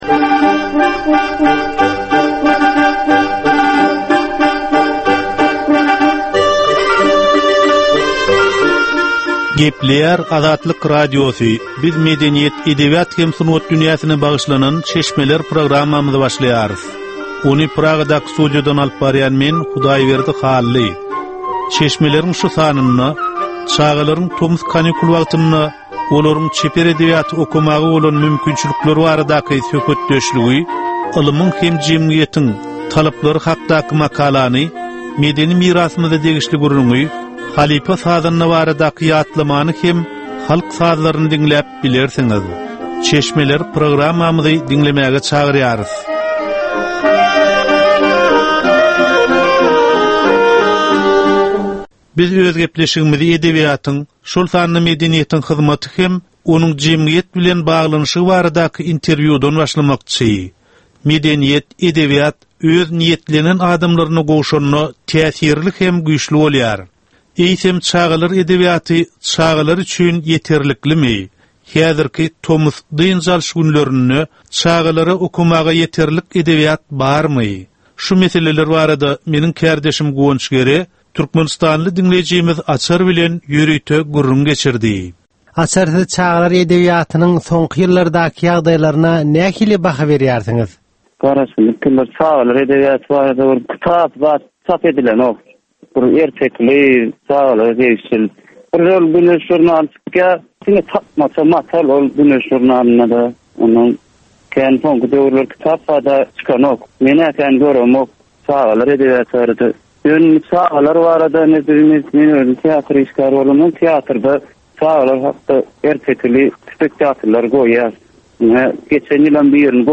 Edebi, medeni we taryhy temalardan 25 minutlyk ýörite geplesik.